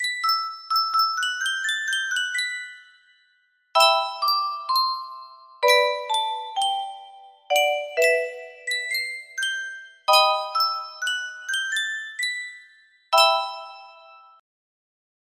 Yunsheng Music Box - Tchaikovsky The Queen of Spades 6087 music box melody
Full range 60